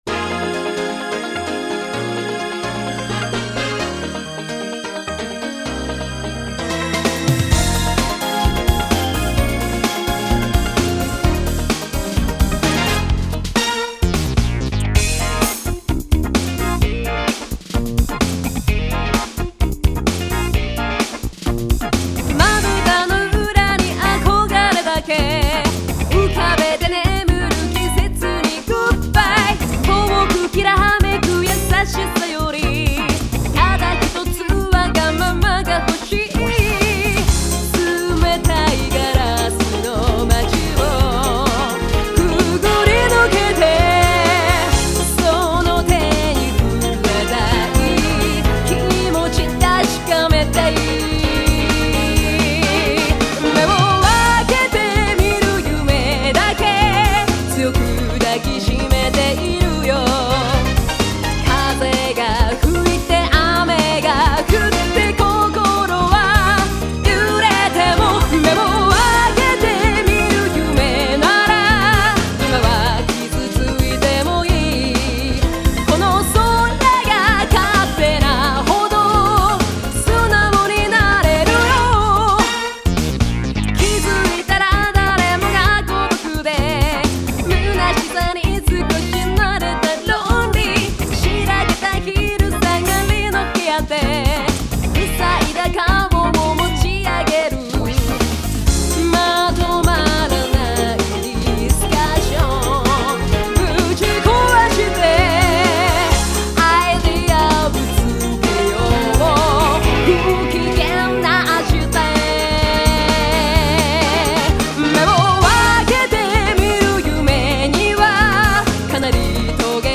Quinta sigla di chiusura